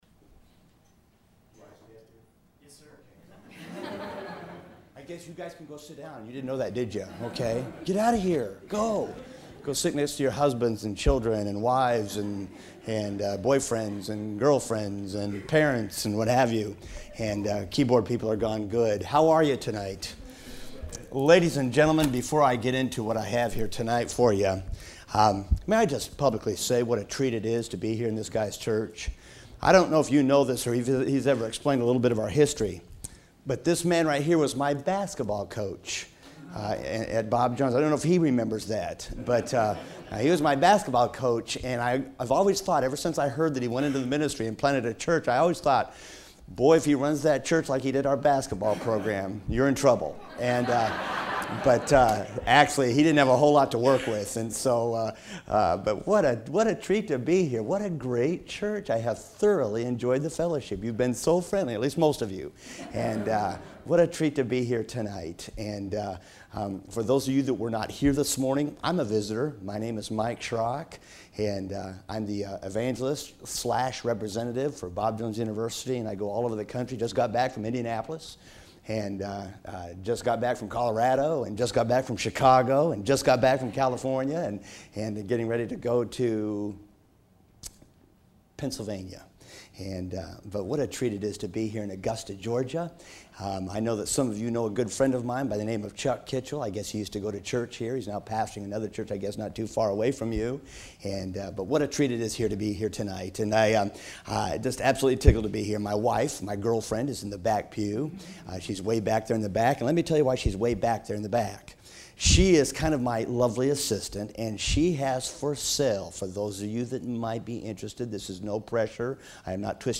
Sermons from visiting guests or other speakers